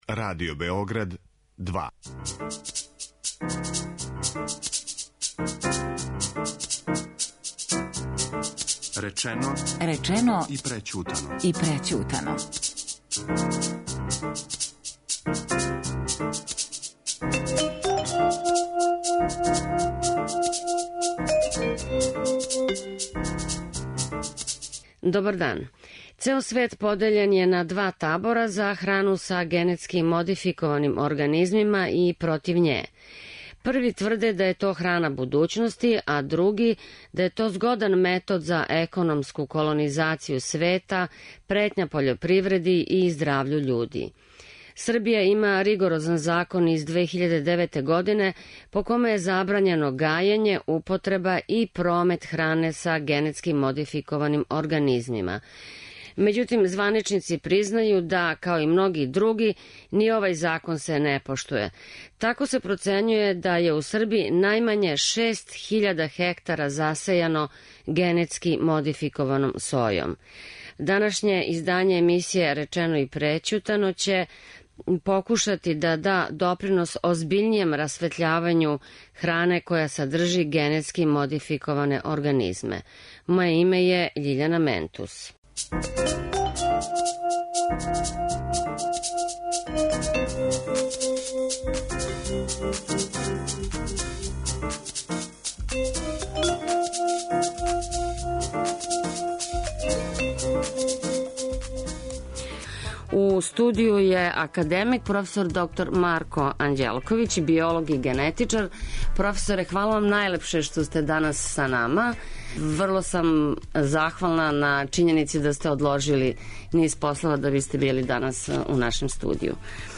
и дописници из Немачке, Мађарске и Словеније.